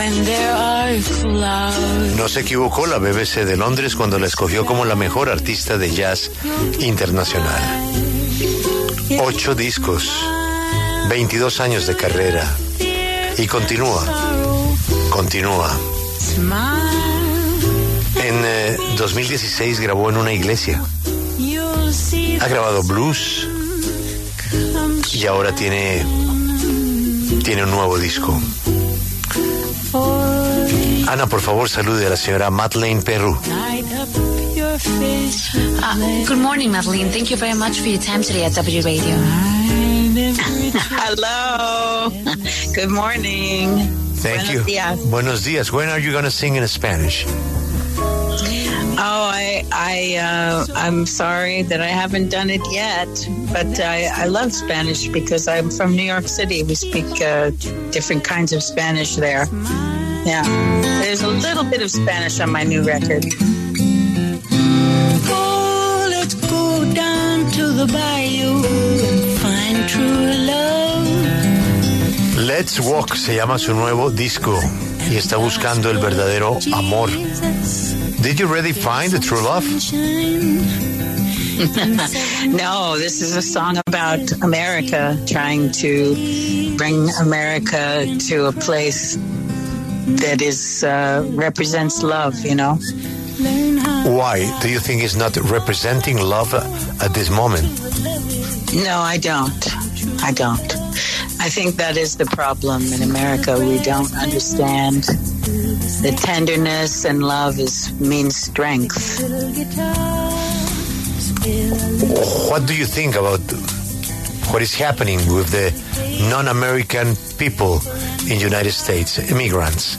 La reconocida artista de jazz, Madeleine Peyroux, conversó con La W sobre el sentido de sus canciones y sus próximos shows en Madrid, España.